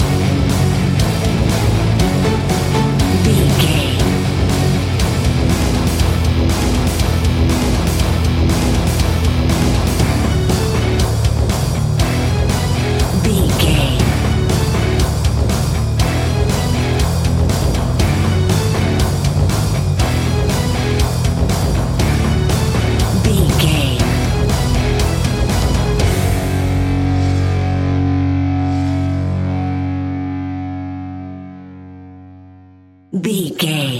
Epic / Action
Fast paced
Aeolian/Minor
F#
hard rock
heavy metal
horror rock
instrumentals
Heavy Metal Guitars
Metal Drums
Heavy Bass Guitars